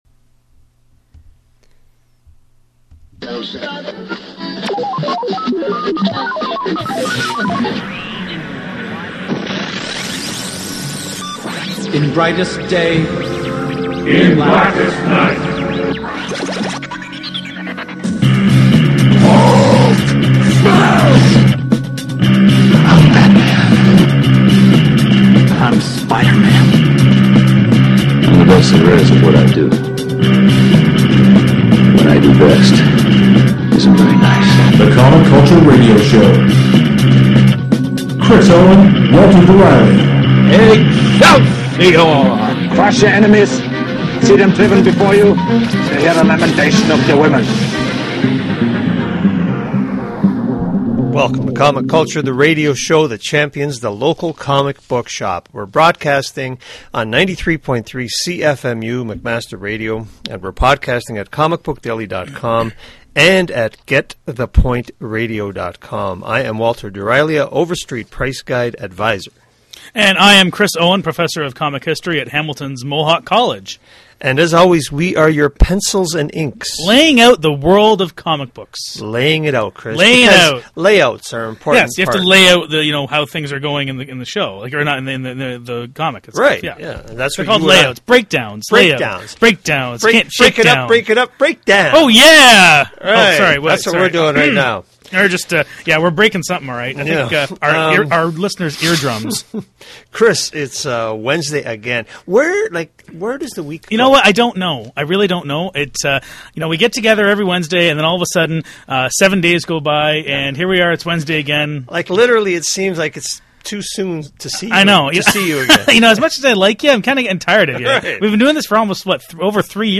The boys also do their monthly picks out of the Previews catalog. So please kick back, relax and enjoy Comic Culture, the radio show full of possibilities.